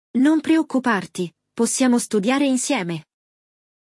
No episódio de hoje, você vai ouvir um diálogo entre dois amigos que conversam sobre um exame. Enquanto um deles está preocupado com a prova, o outro se oferece para ajudar nos estudos.